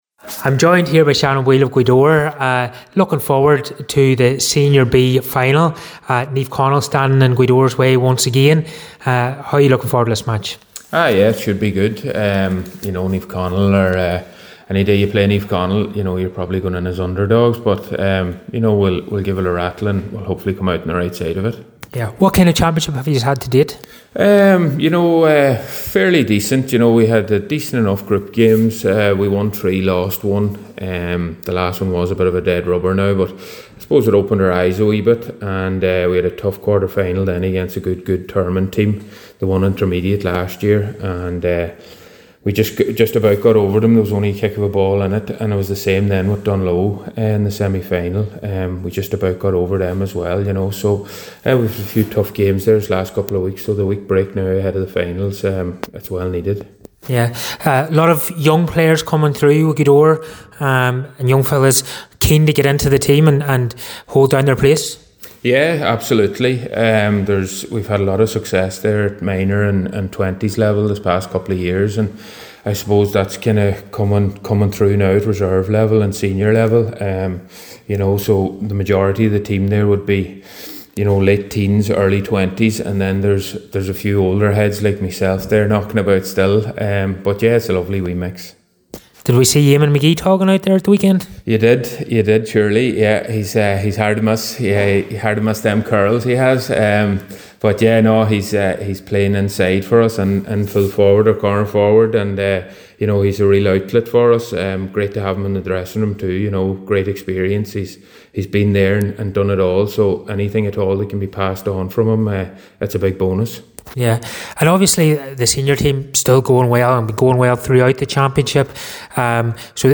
was at the press event for Highland Radio Sport and caught up with both camps.